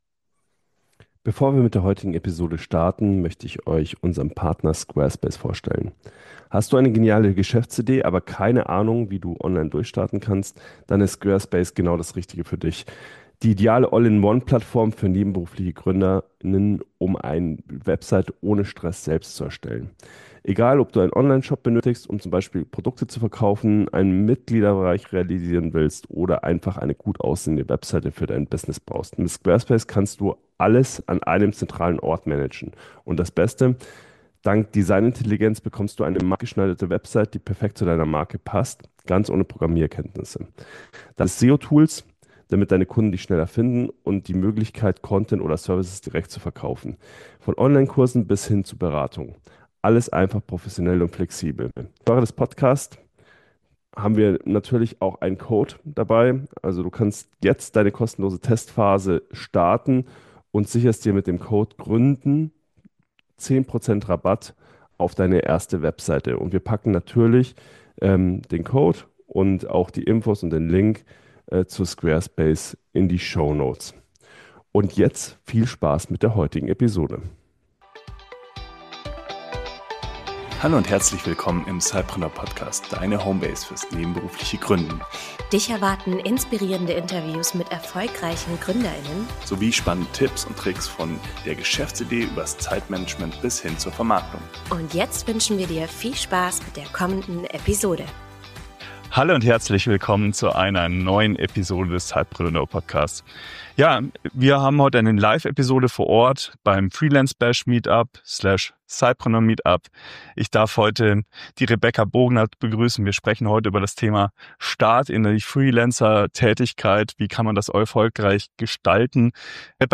In dieser Live-Podcast-Episode vom Freelance Bash / Sidepreneur Meetup